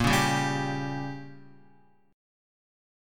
Bbm6 Chord